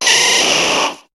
Cri de Florizarre dans Pokémon HOME.